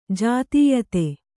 ♪ jātīyate